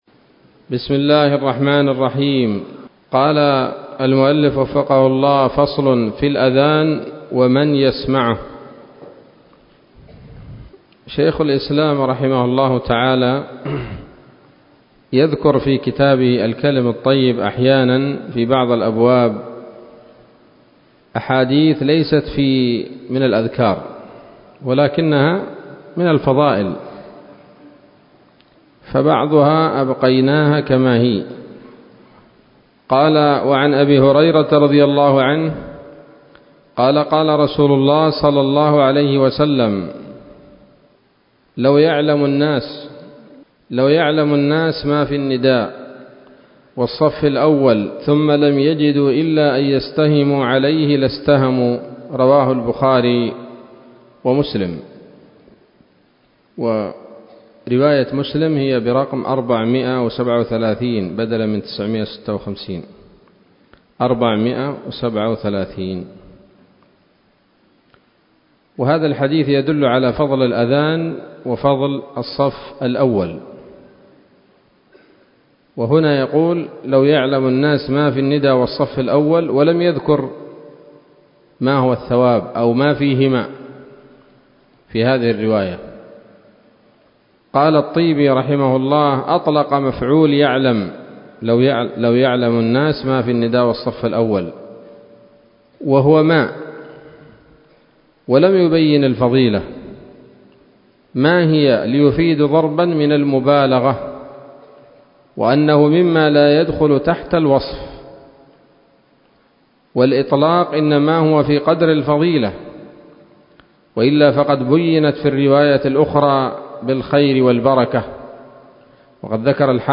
الدرس الثالث والعشرون من رياض الأبرار من صحيح الأذكار